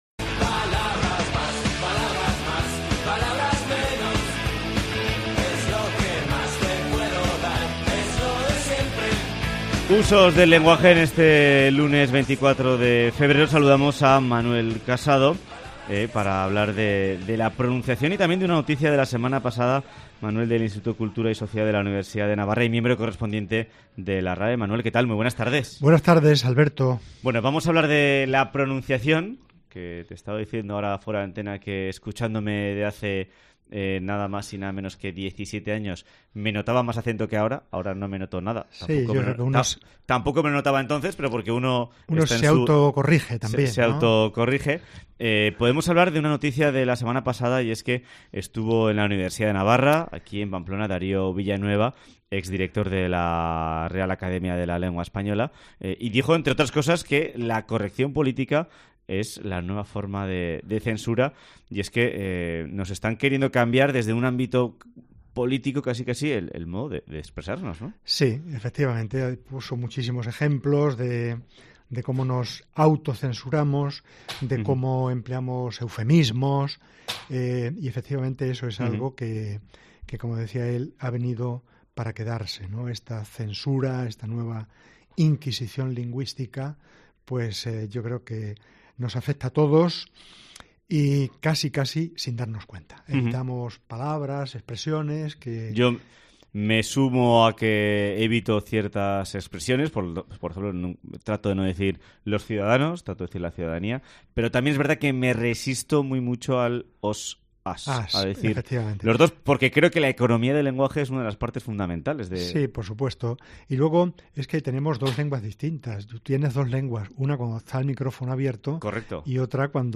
Escucha la sección y comprueba las diferentes pronunciaciones y cuales no son correctas.